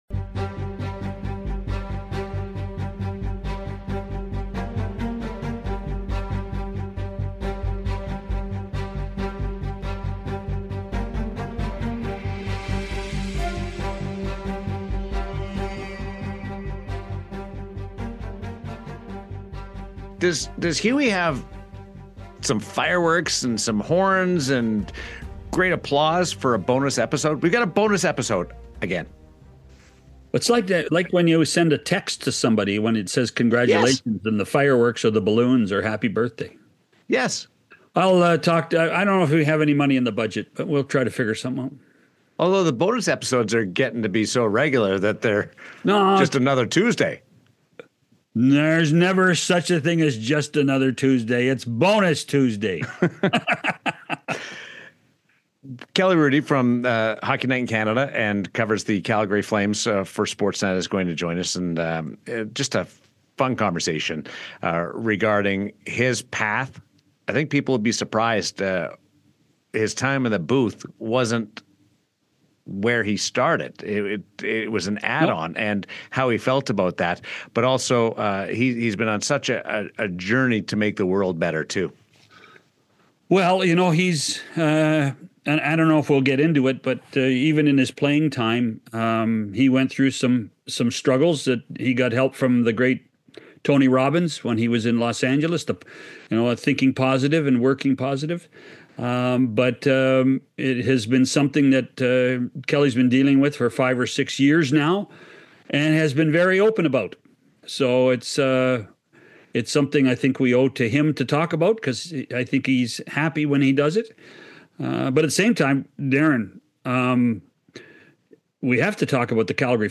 Broadcaster and former NHL goaltender Kelly Hrudey joins the show for an in-depth look at the Calgary Flames' season, including Nazem Kadri’s evolution and the vital role MacKenzie Weegar has played in the team’s turnaround.